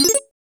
powerup.wav